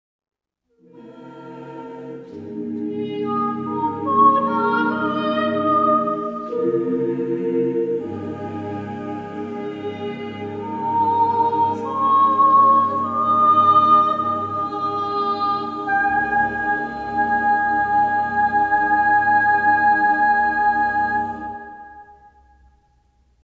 SATTBB (6 voices mixed).
Contemporary. Secular. Cycle.
Mood of the piece: melodious ; mystical ; contemplative
Soloist(s): S(1) (1 soloist(s))